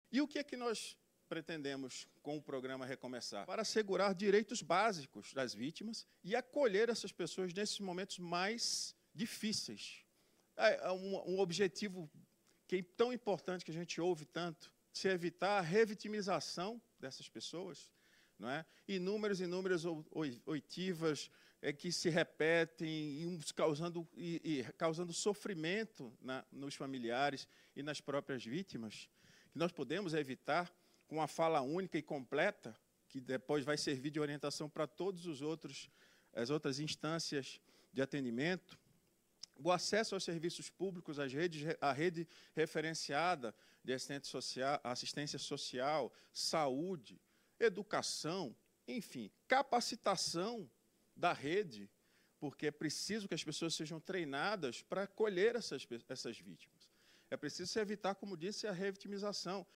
André Garcia, Secretário Nacional de Políticas Penais, aponta a importância do programa no combate à revitimização — Ministério da Justiça e Segurança Pública